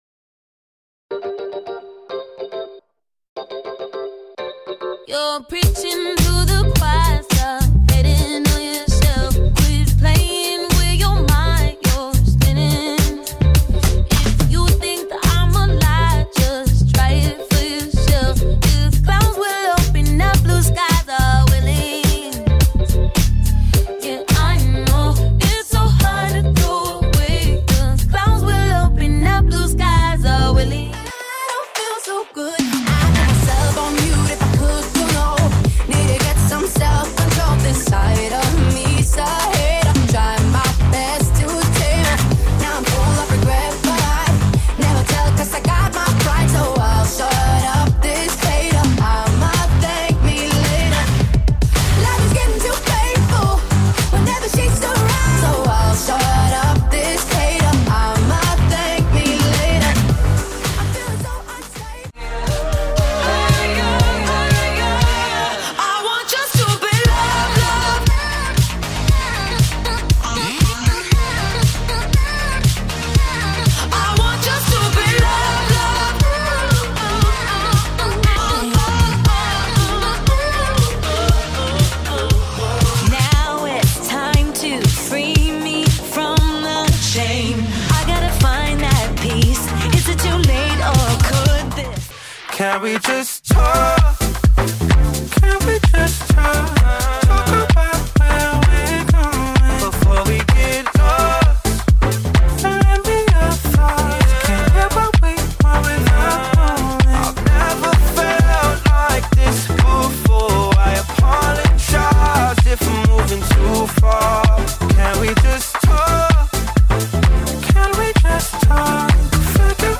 Upbeat Happy Hour
Upbeat Songs for The Happy Hour